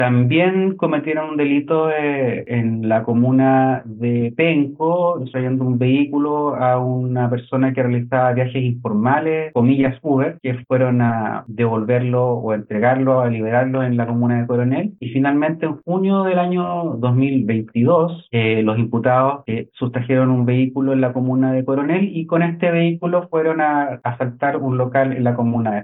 Parte del tour delictual fue explicado por Felipe Calabrano, fiscal de Análisis Criminal.